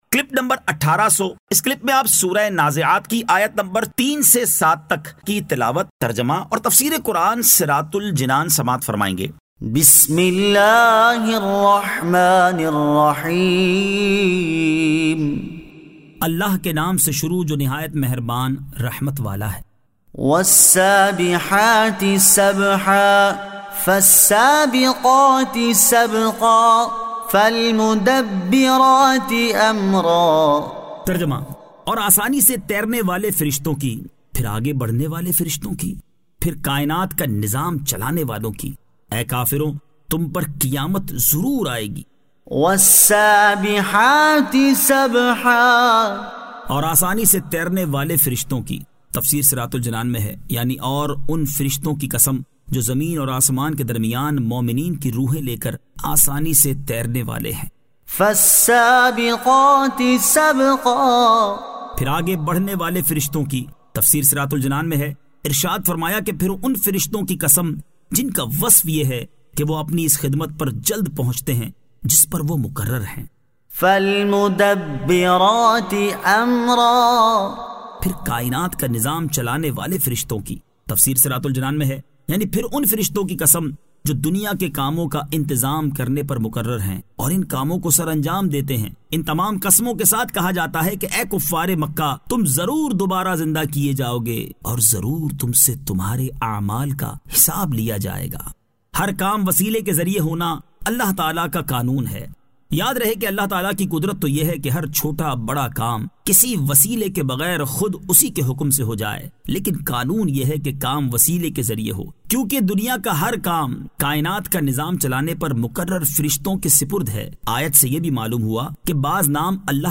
Surah An-Nazi'at 03 To 07 Tilawat , Tarjama , Tafseer